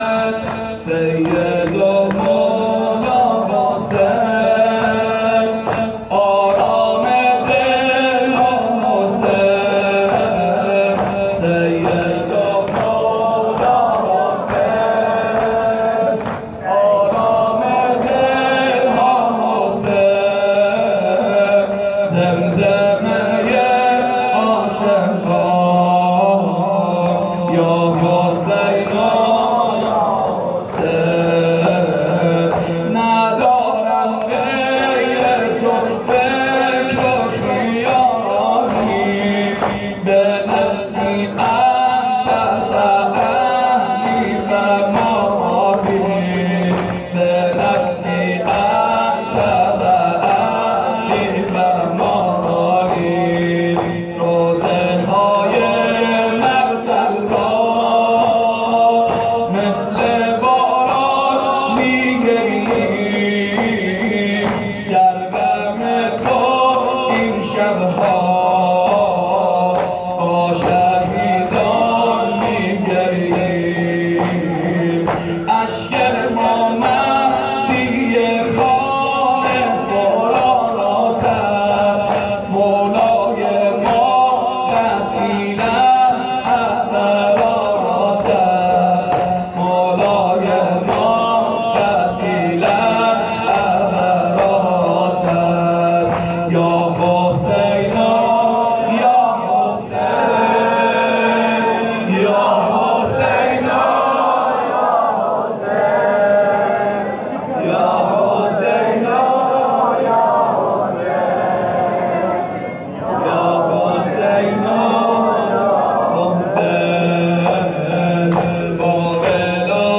دانلودفایلهای صوتی شب پنجم محرم96
2-شور-سیدومولاحسین.mp3